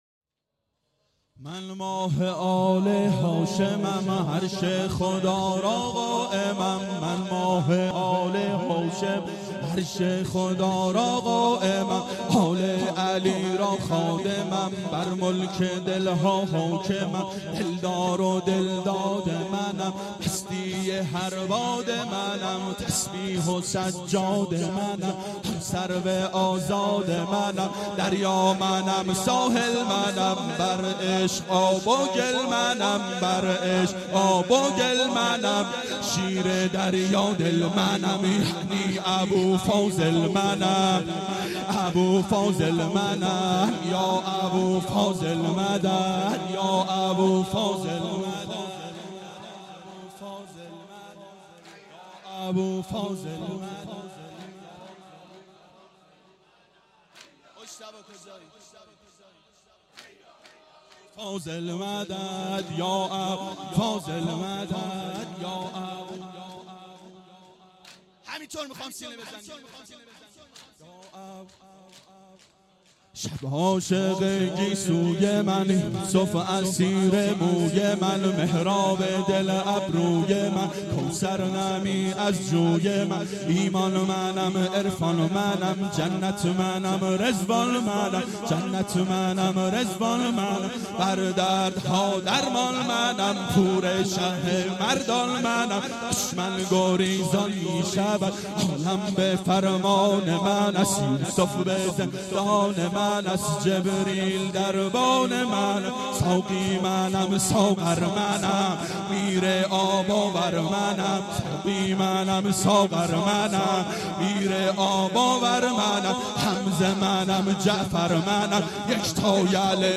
دهه اول صفر سال 1391 هیئت شیفتگان حضرت رقیه سلام الله علیها (شب اول)